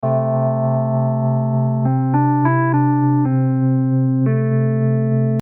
Two notes. F and then G. That line ain’t gonna change for the whole song.
So, again, inspired by Fleetwood Mac, I began with this melody in one of Logic’s built in classic electronic piano sounds, which I ended up using in the chorus:
I kinda wanted this particularly melody to feel a touch behind the beat, so that it felt like it was moving a little out of time.
rhodes.mp3